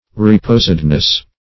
reposedness - definition of reposedness - synonyms, pronunciation, spelling from Free Dictionary